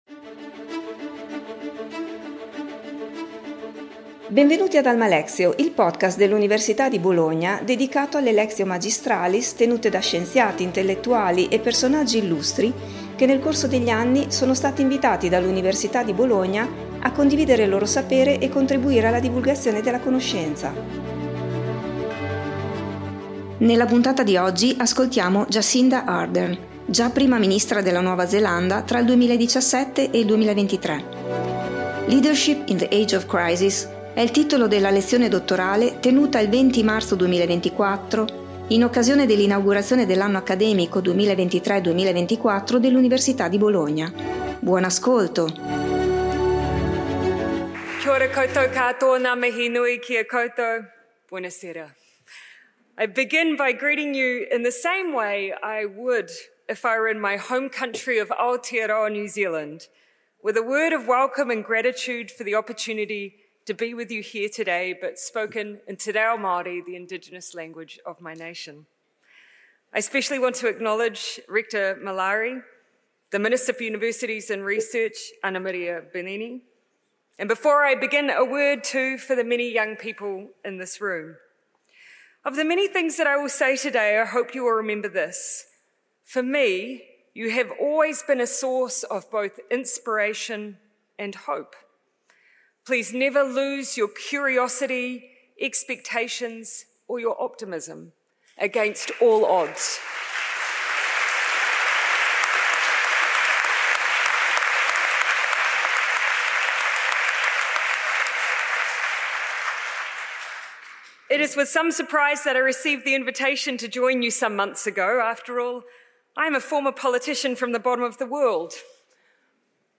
Jacinda Ardern, già Prima Ministra della Nuova Zelanda tra il 2017 e il 2023, ha tenuto la sua Lectio Magistralis il 20 marzo 2024 in occasione dell’Inaugurazione dell’Anno Accademico 2023-2024 dell’Università di Bologna.